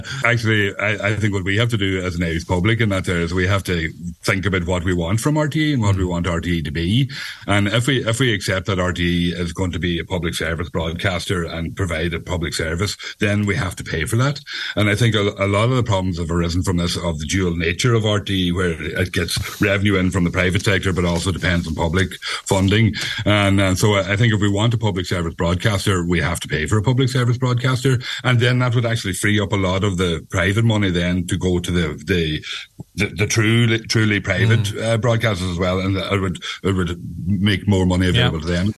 Speaking on this morning’s Nine ’til Noon show, he said the conversation needs to be kept a light.